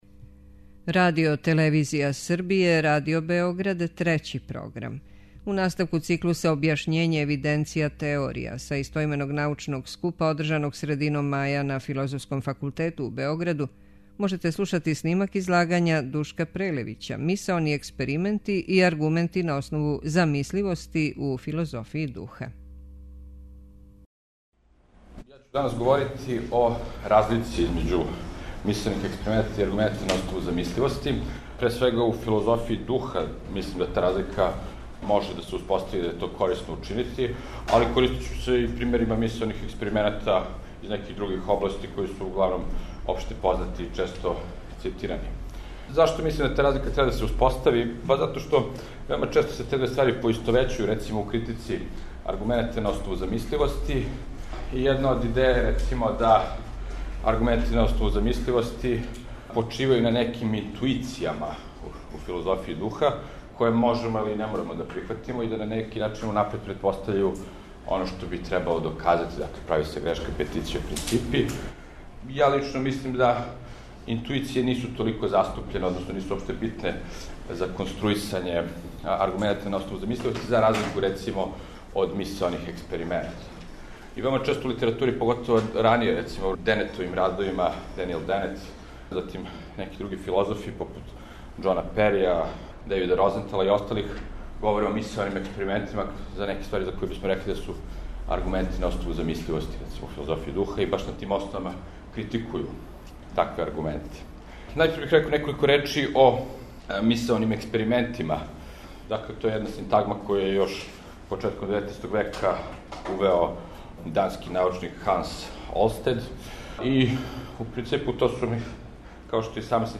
Научни скупови